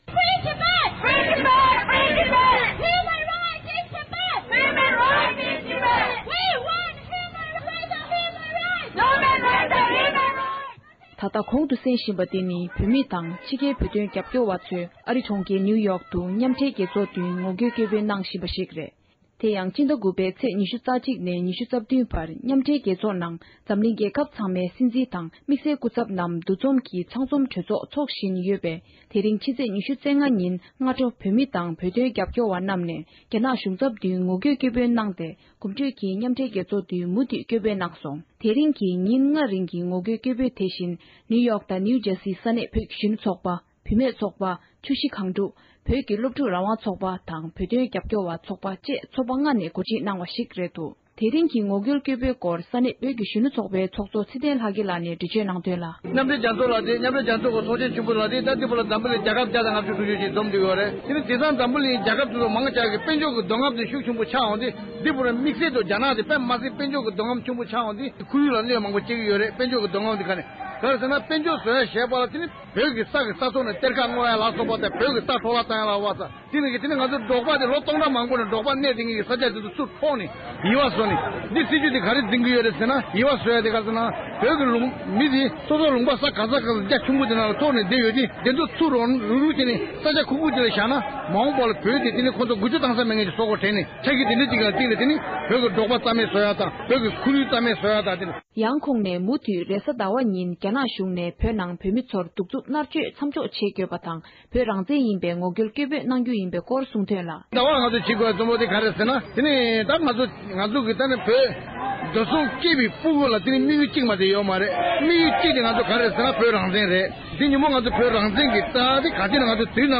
མཉམ་འབྲེལ་རྒྱལ་ཚོགས་ཀྱི་མདུན་ངོ་རྒོལ་སྐད་འབོད།
སྒྲ་ལྡན་གསར་འགྱུར།